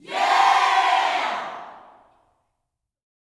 YEAH  03.wav